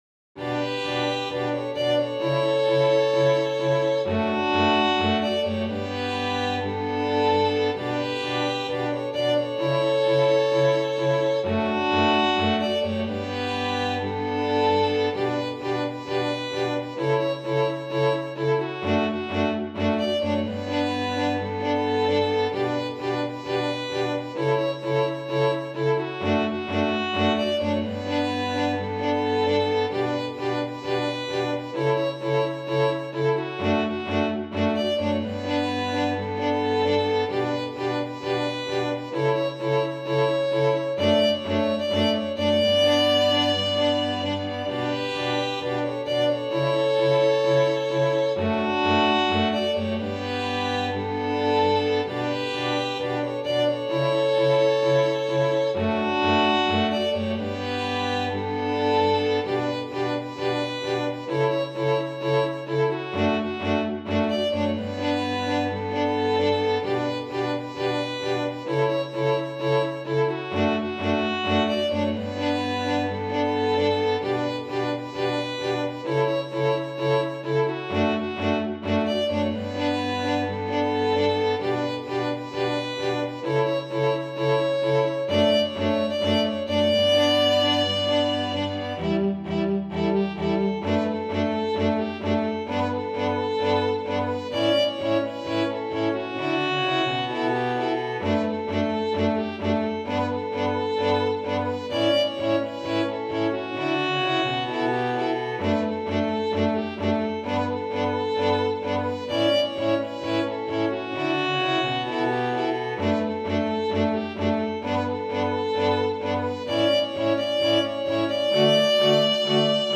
● Violino I
● Violino II
● Viola
● Violoncelo